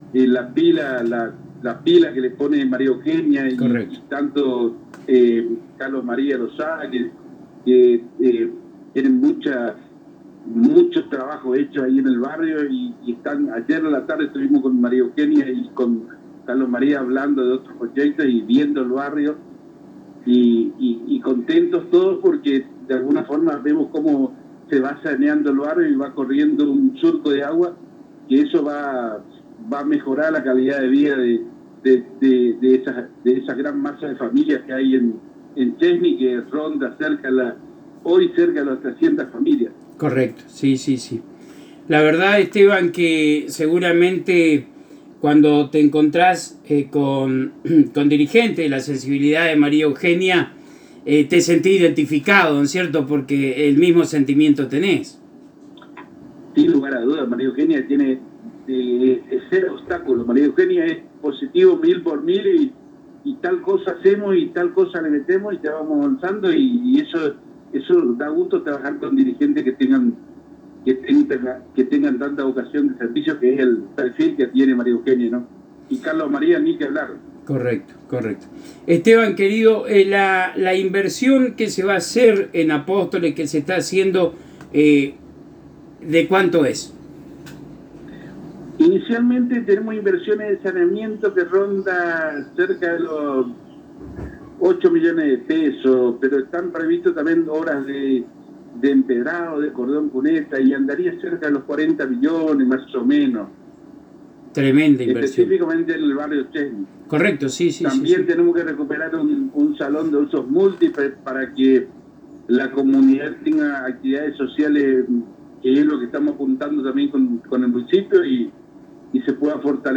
En diálogo con la ANG y el programa «Lo Mejor de la Ciudad» Esteban Romero contó las importantes obras que se están realizando en el Barrio Chezni de la Ciudad de Apóstoles.